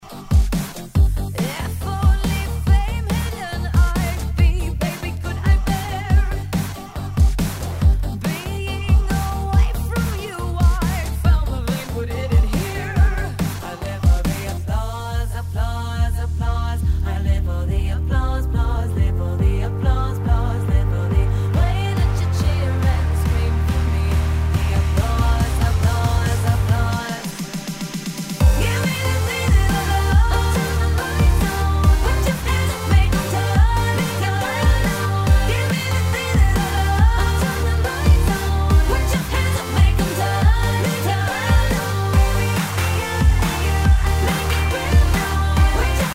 Tag       R&B R&B